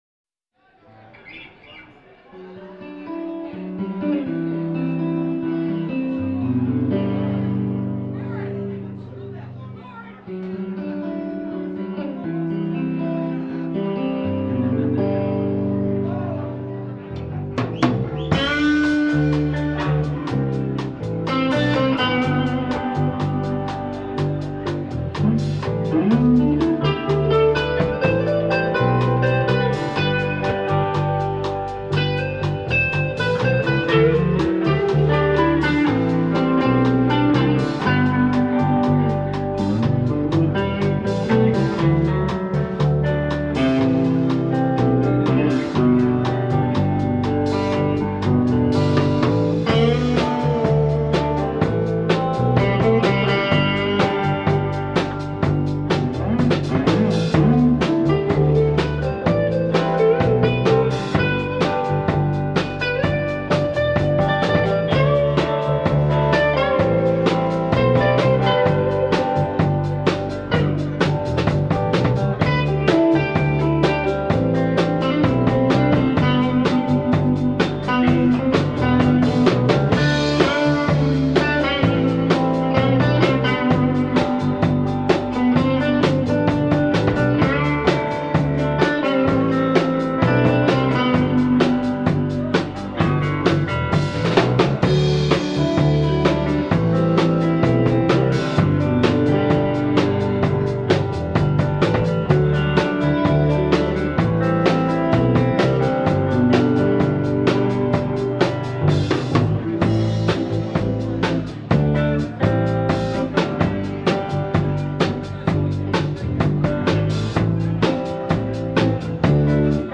on VHS tape & remastered
vocals
guitar
bass
drums